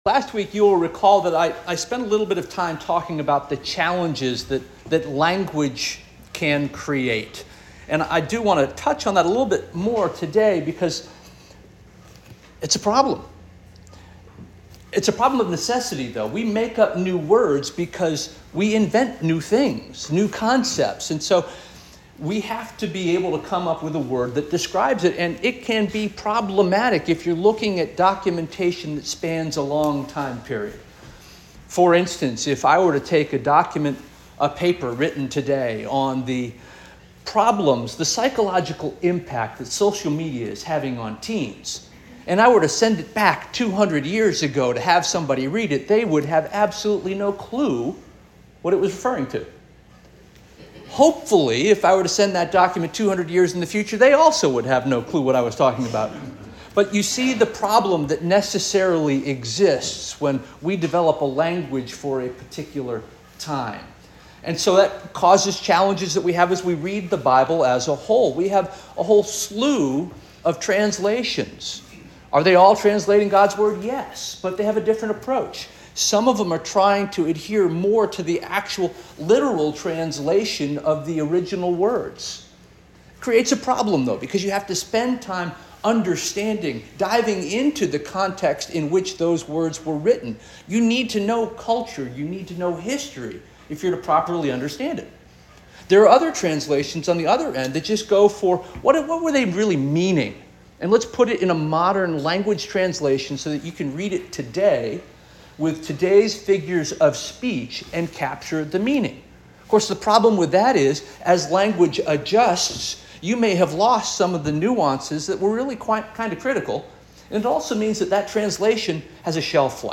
September 22 2024 Sermon - First Union African Baptist Church